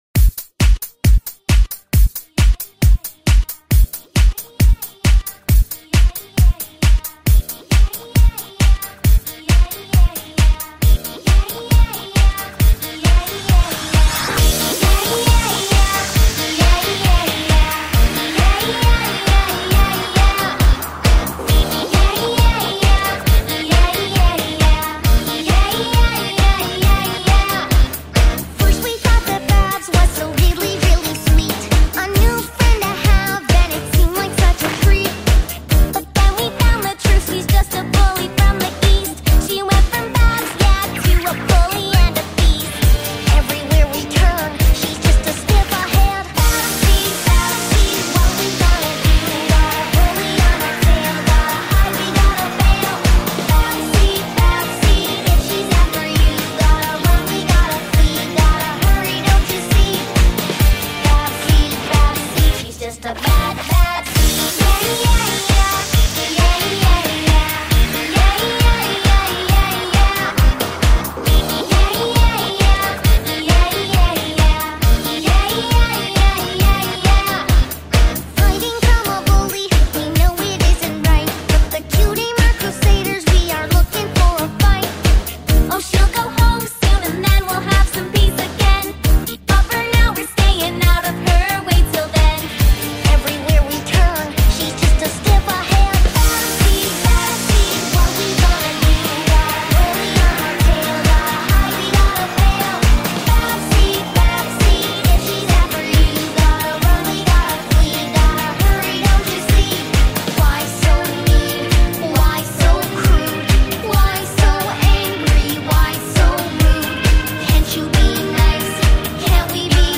So i just had to remix it, plus its just soooo catchy.